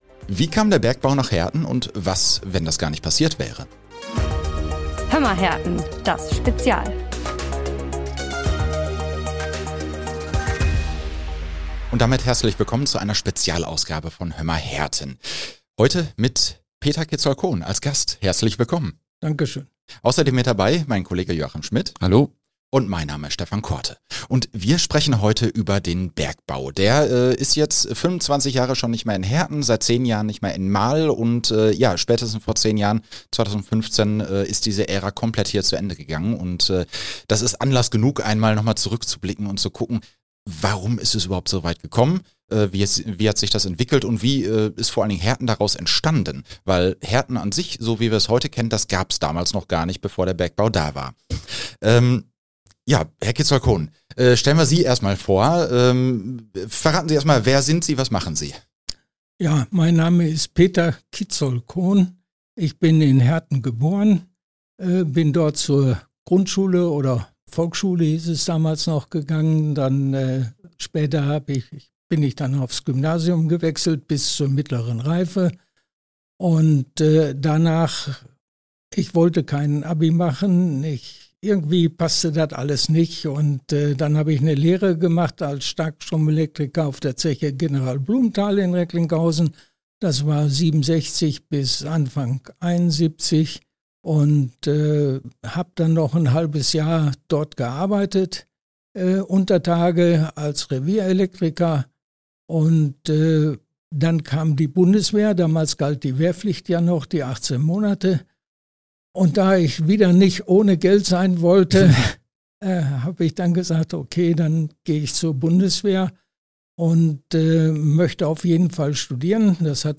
Ein spannendes Gespräch, nicht nur für ehemalige Püttis.